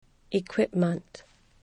equipment  IkwIpmənt